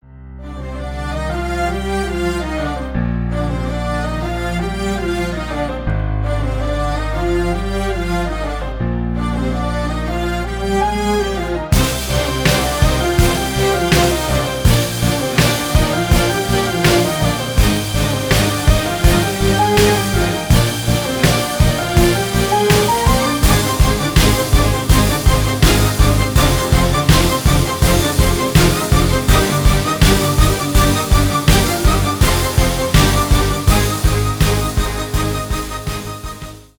Рингтоны без слов
инструментальные , восточные , скрипка